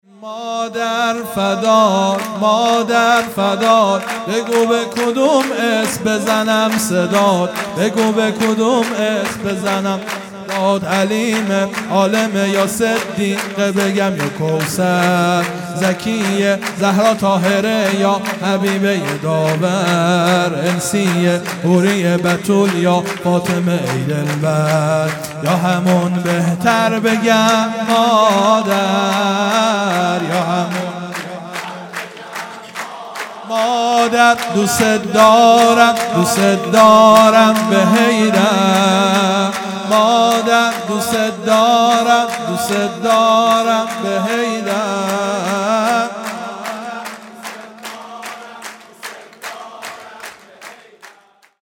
هیئت دانشجویی فاطمیون دانشگاه یزد
سرود
ولادت حضرت زهرا (س) | ۱۵ اسفند ۱۳۹۶